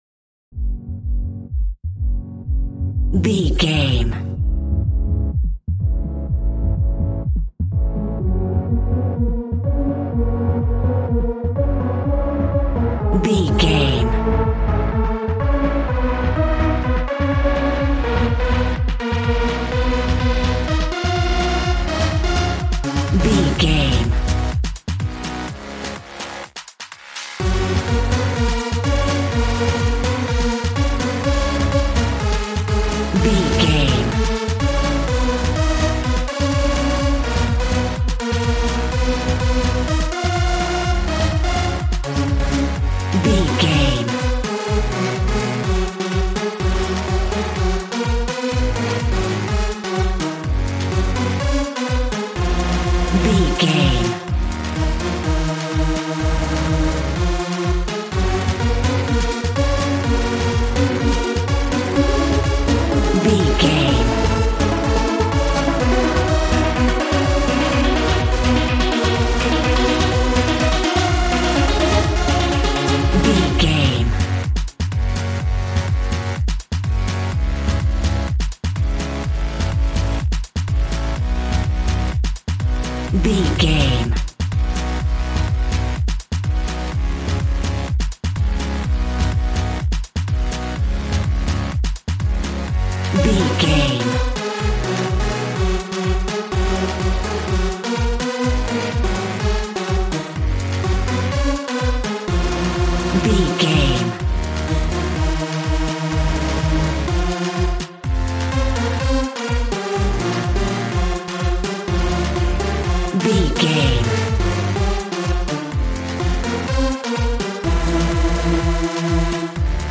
Ionian/Major
G♭
Fast
groovy
energetic
synthesiser
drums